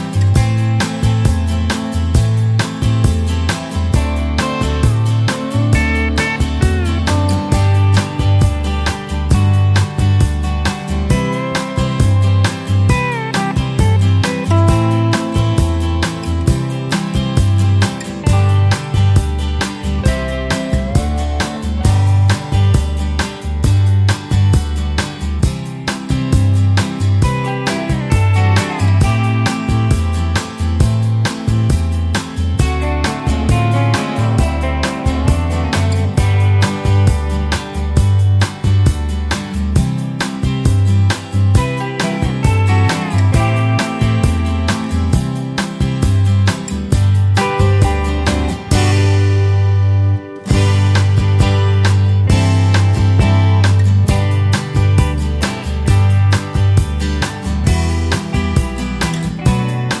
no Backing Vocals Soft Rock 6:18 Buy £1.50